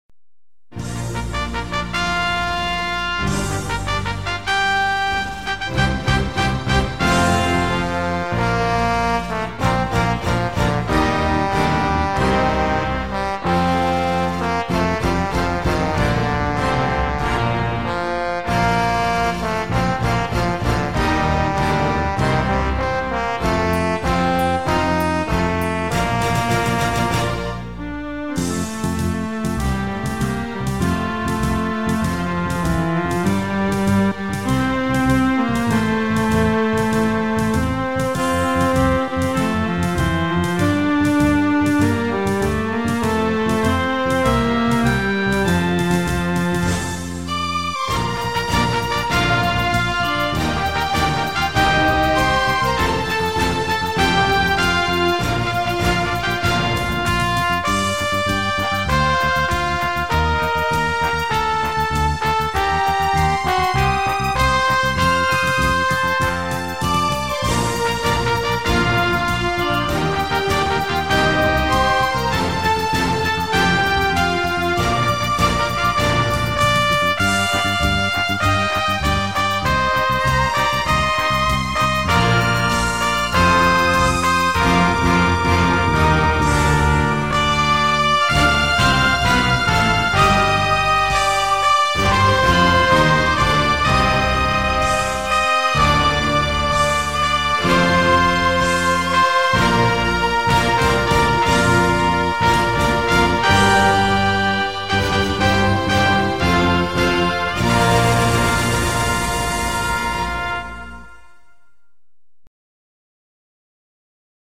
File nhạc không lời